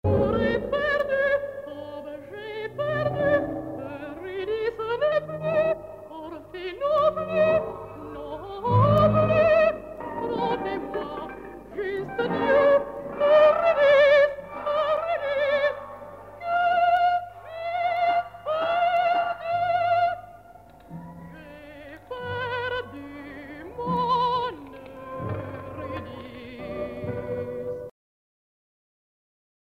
a musique concrète opera.